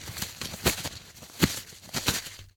inv_bandage.ogg